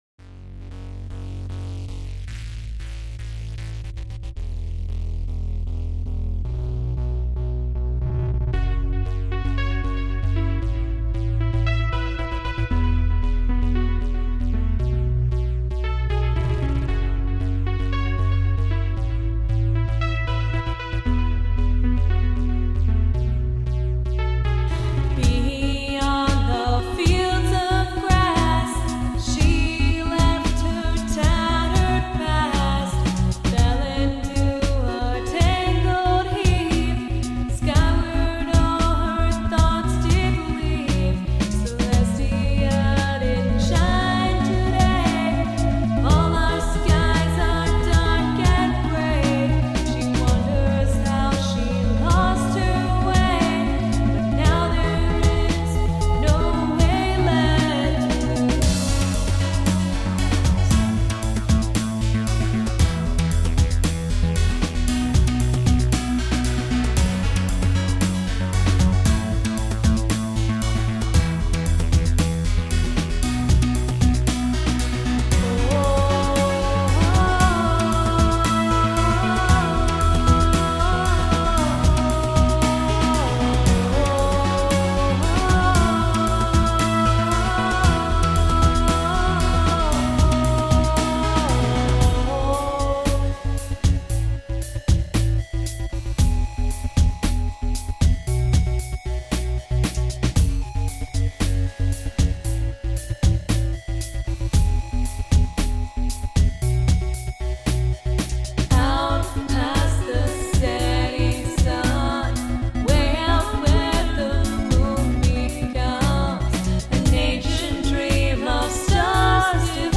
randomly added harmonies